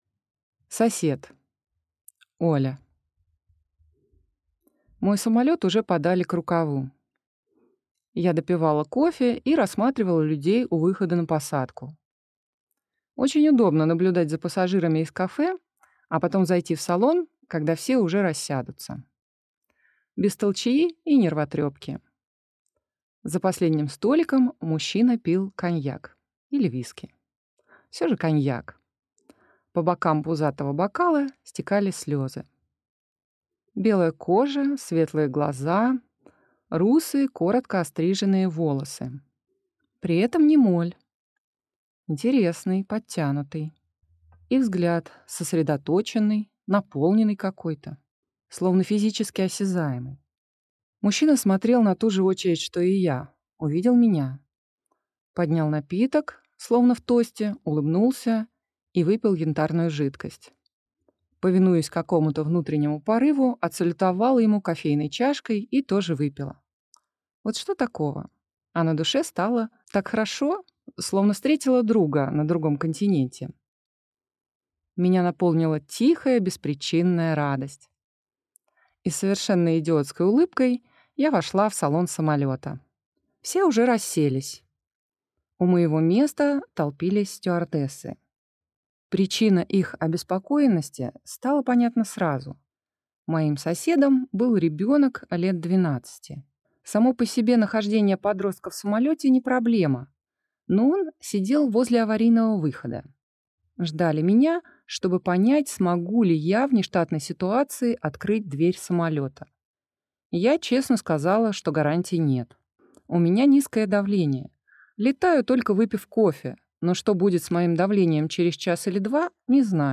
Аудиокнига SOS. Стойкий солдатик | Библиотека аудиокниг